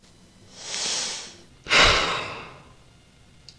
女声的反应 " 叹息5女声
描述：一名年轻女子叹气，可能是沮丧，愤怒，厌倦，愤怒等。 使用我的Turtlebeach EarforceX12耳机录制，然后在Audacity中编辑。
标签： 语音 女孩 言语 呼吸 发声 叹息 女性 女人 反应 呼吸
声道立体声